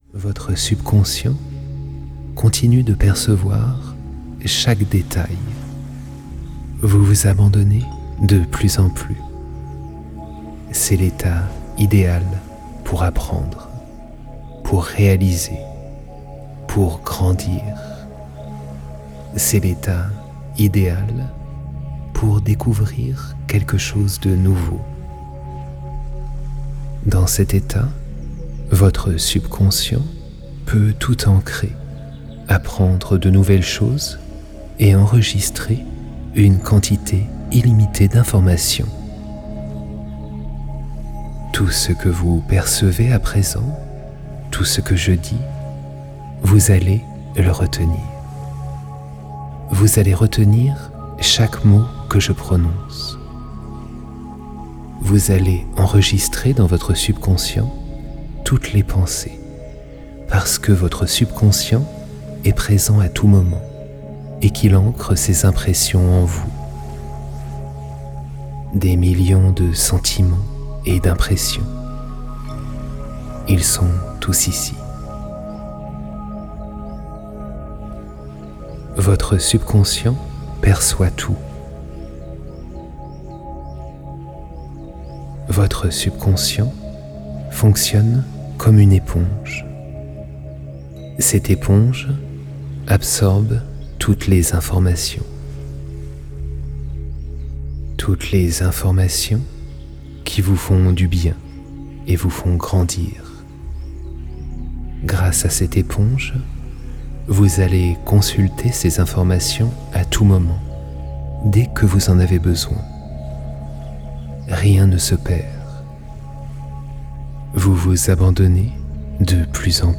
Pendant cette séance, vous entendrez des informations subliminales qui vous aideront à prendre de nouvelles habitudes saines et à établir de nouvelles doctrines en matière d'alimentation.